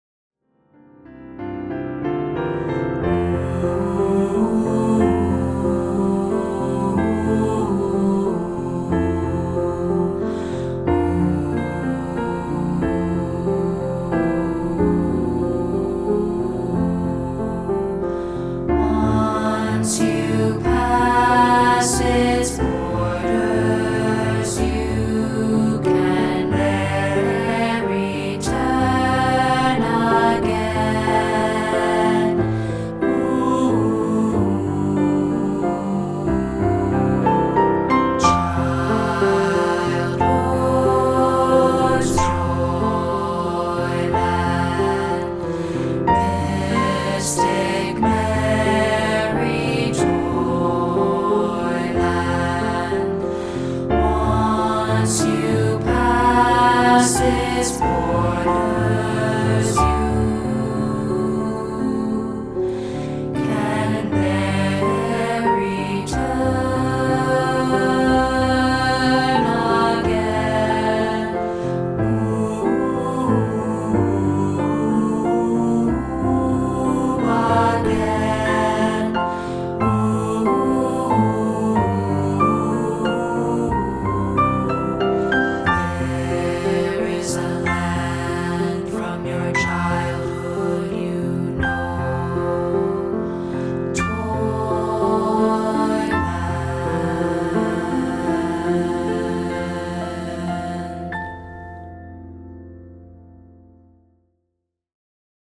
Here's a rehearsal track of part 3B, isolated.